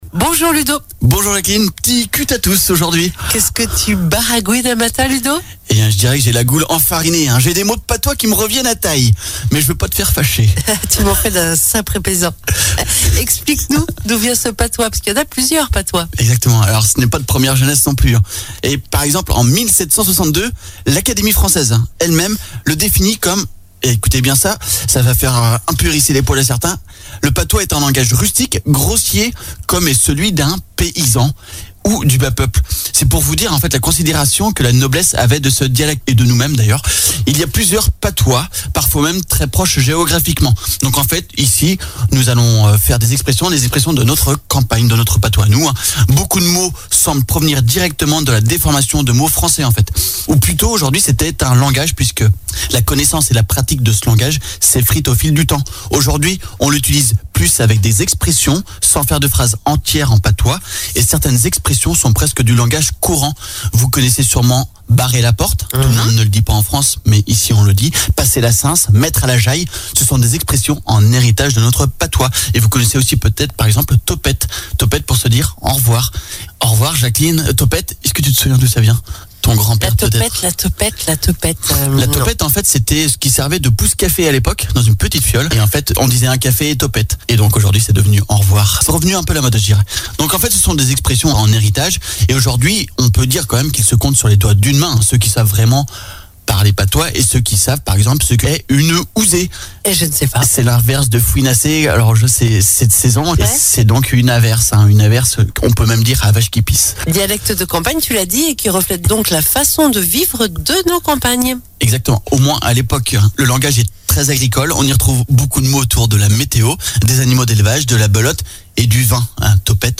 En patois